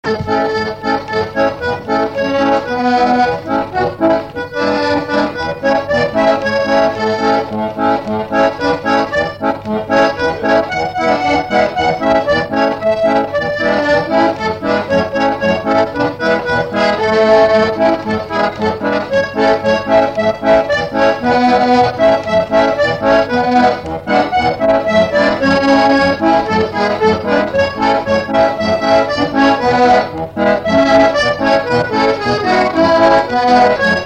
Instrumental Usage d'après l'analyste gestuel : danse ;
Pièce musicale inédite